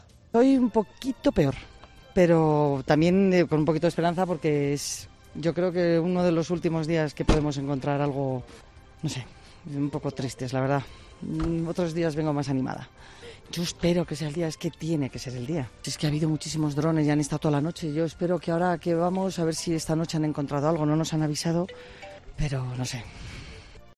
"Estoy un poco triste, otros días vengo más animada”, añadía con al voz apagada.